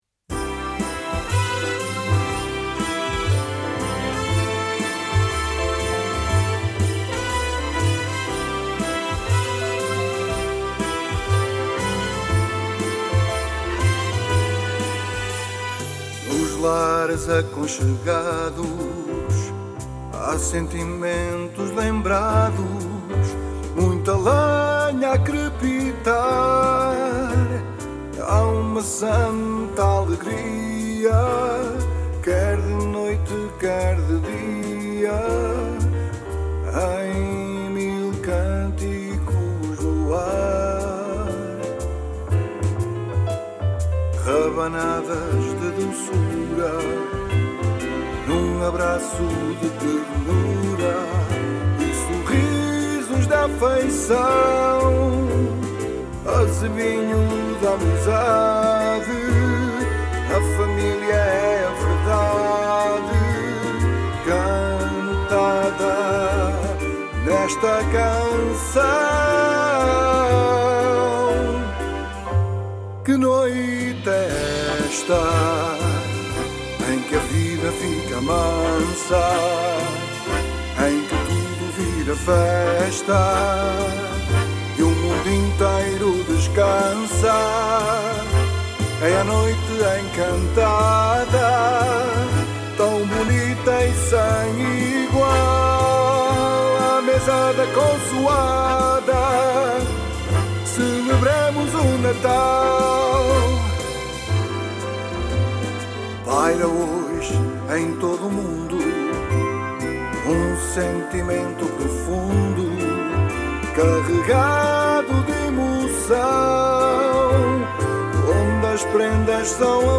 canção natalícia
uma canção natalícia original, recentemente gravada